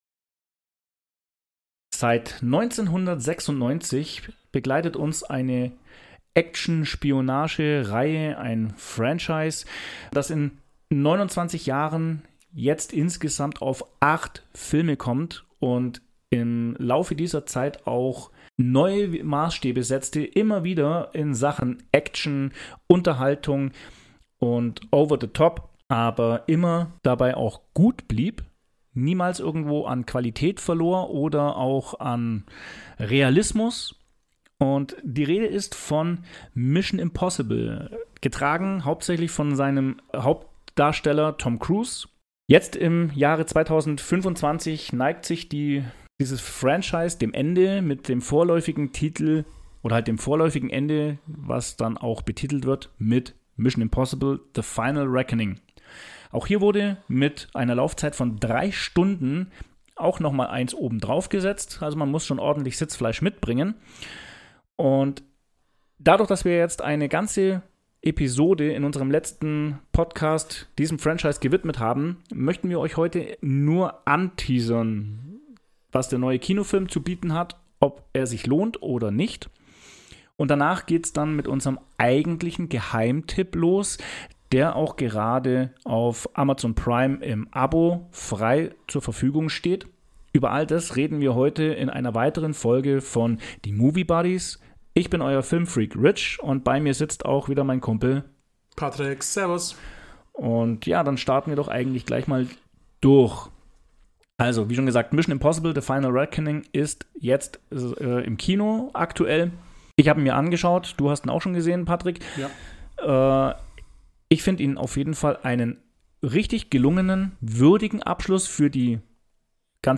In der heutigen Folge sprechen die beiden Filmliebhaber über einen wunderschönen Familienfilm, der leider zu wenig Aufmerksamkeit erhalten hat und zu weit unter dem Radar lief.